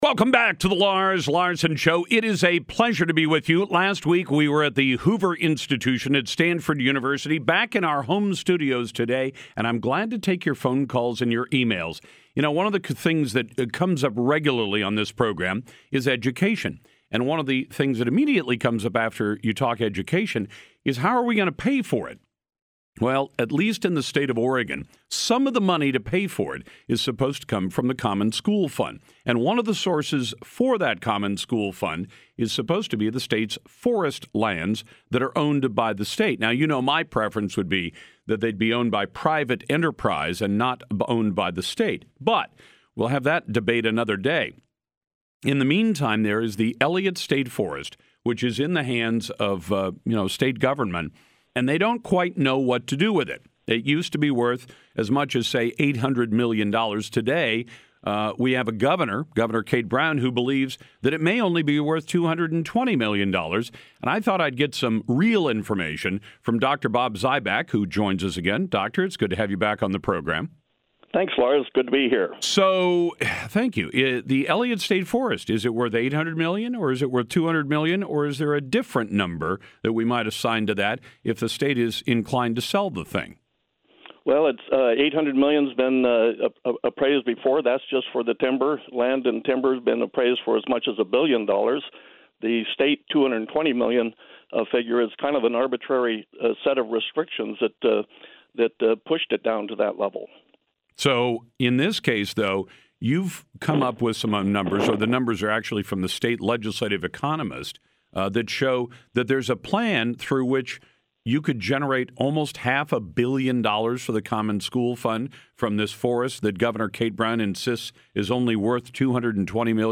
RADIO INTERVIEWS
Interviews.